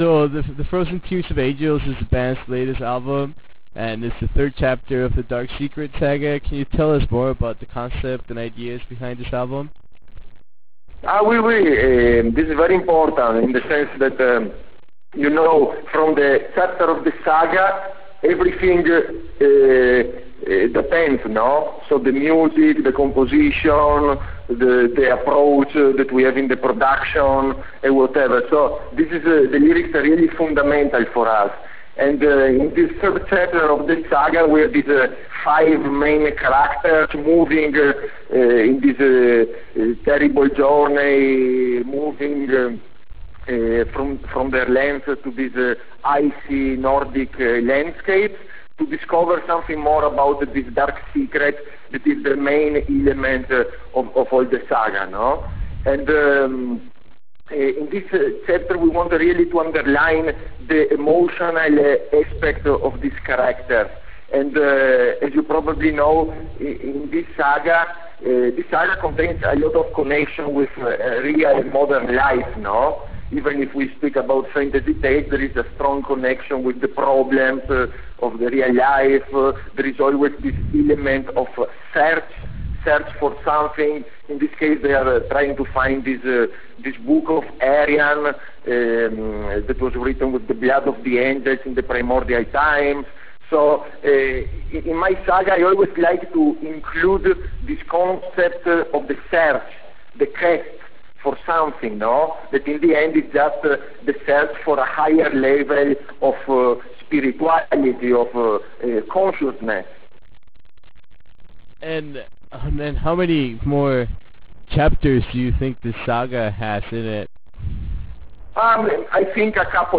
Interview with Rhapsody of Fire - Luca Turilli
Interview with Rhapsody Of Fire - Luca Turilli.wav